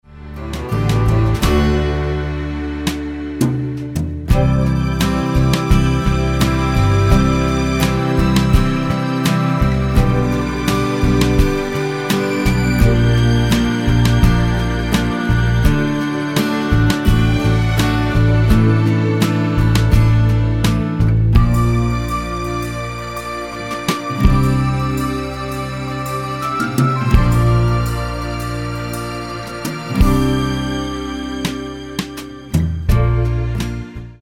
Tonart:D ohne Chor